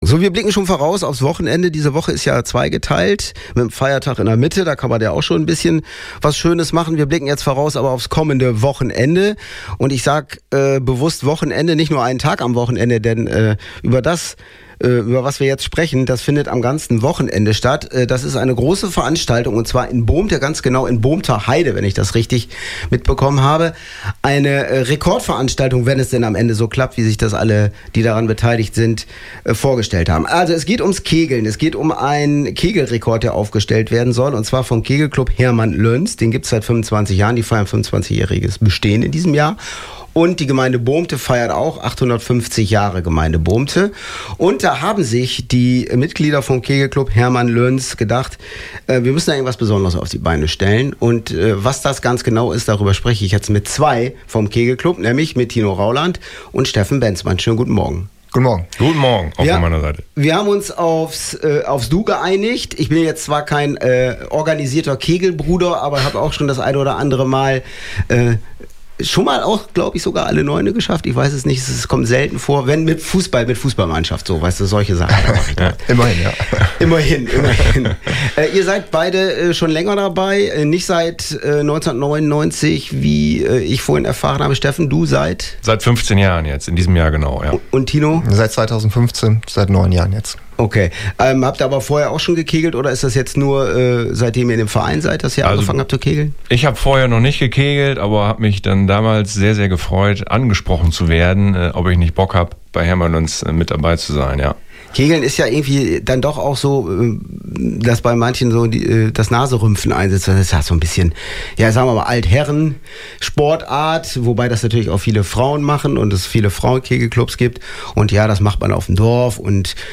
2024-04-29 OS-Radio Mitschnitt KC Hermann Löns Weltrekordversuch Download